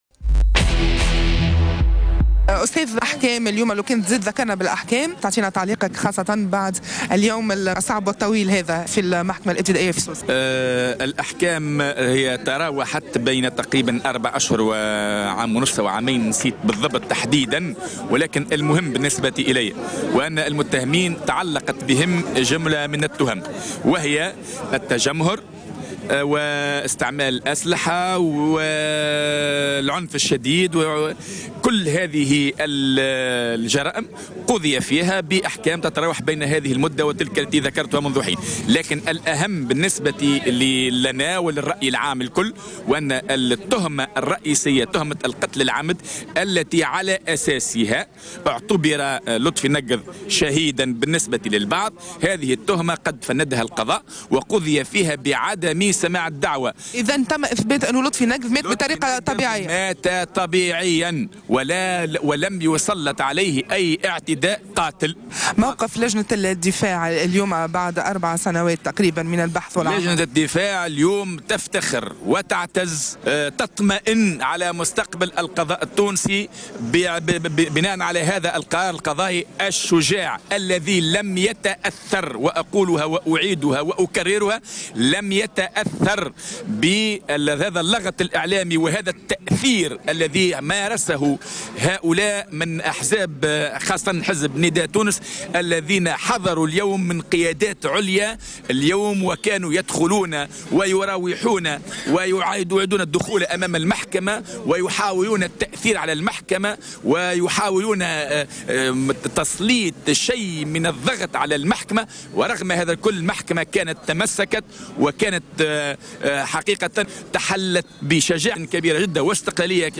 وأكدت عبر اتصال هاتفي في "بوليتيكا" اليوم، أن التقاضي مازال متواصلا و"الخاتمة لمن يضحك في الآخر"، بحسب تعبيرها.